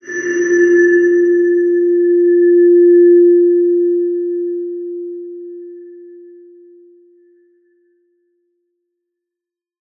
X_BasicBells-F2-mf.wav